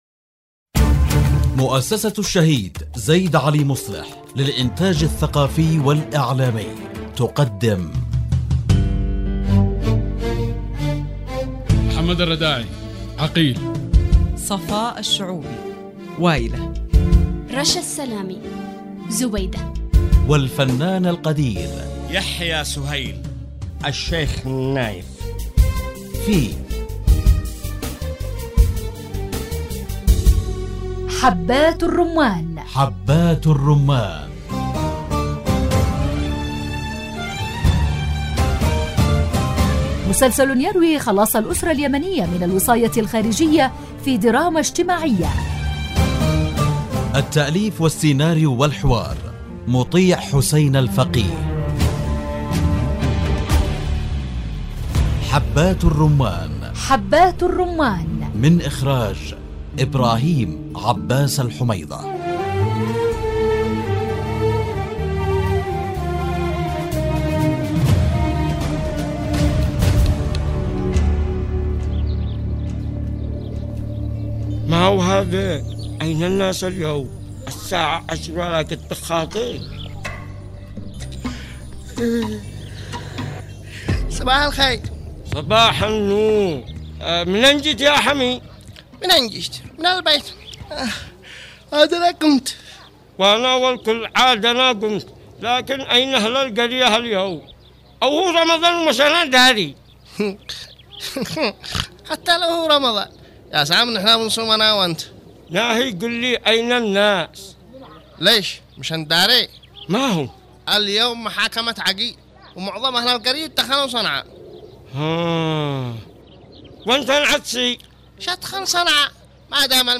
مسلسل يحكي خلاص الأسرة اليمنية من الوصاية الخارجية في دراما اجتماعية مع ألمع نجوم الشاشة اليمنية